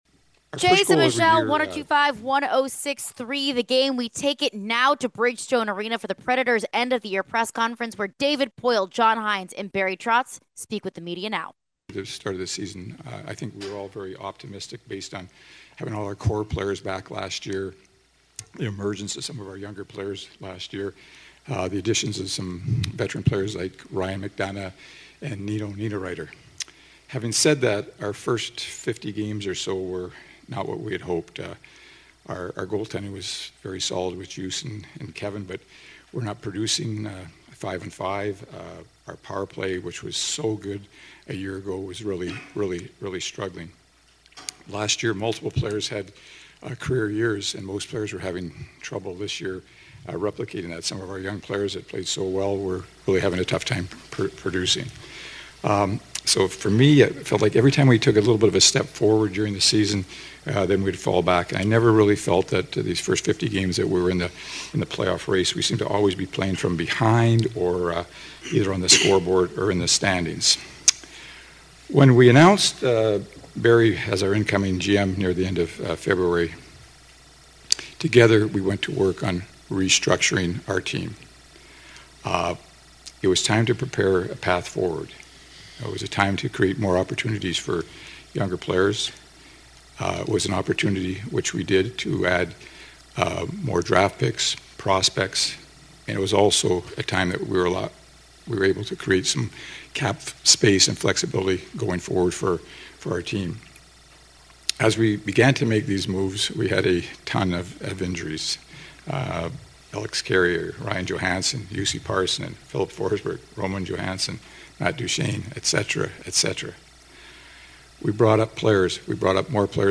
General Manager David Poile, Barry Trotz, & Head Coach John Hynes had their end of season press conference & spoke on the future of this team.